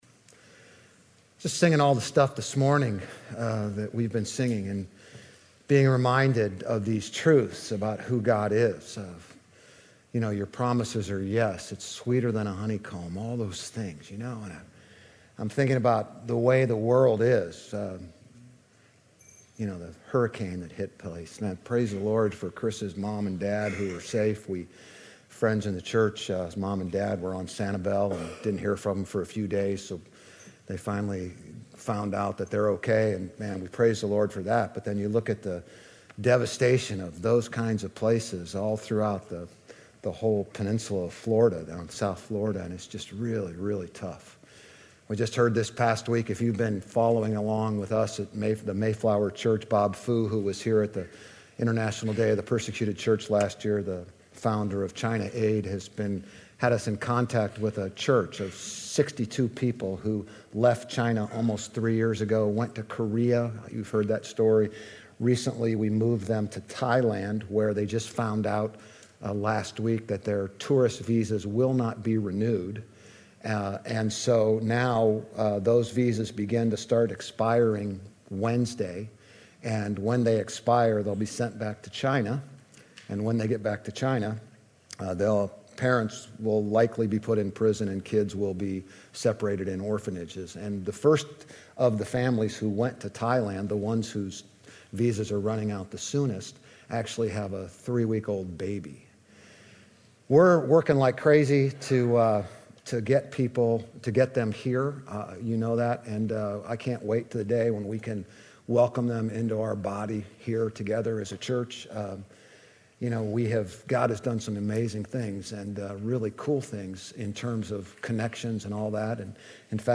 GCC-OJ-October-2-Sermon.mp3